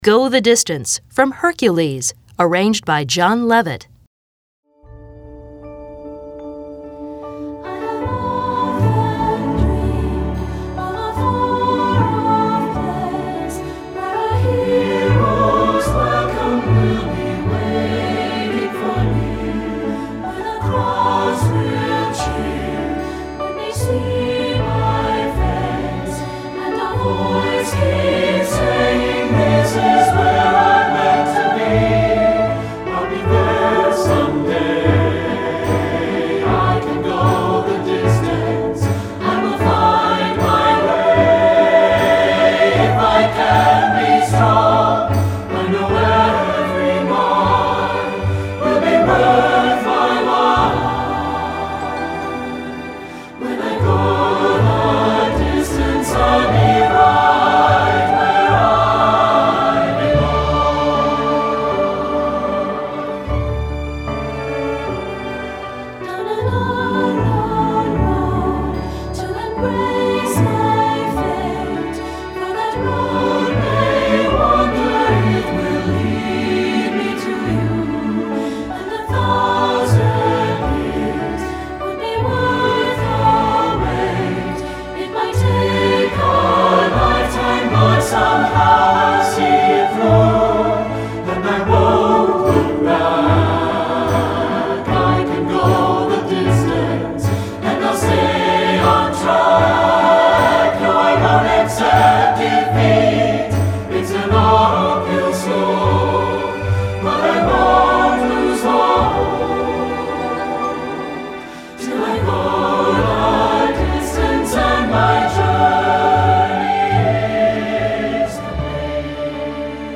SSA